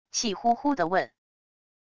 气呼呼的问wav音频